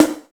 35 SNARE 2.wav